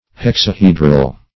Search Result for " hexahedral" : The Collaborative International Dictionary of English v.0.48: Hexahedral \Hex`a*he"dral\, a. In the form of a hexahedron; having six sides or faces.